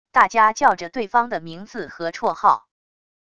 大家叫着对方的名字和绰号wav音频